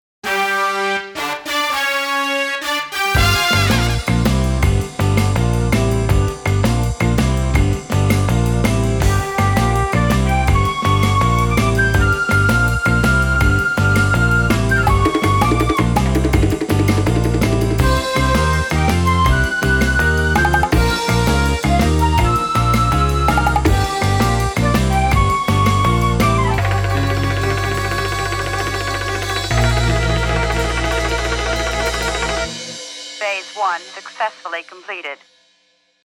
CLASSIC SONG COVERS
All Keyboards and Guitars
Fender ’63 Precision Bass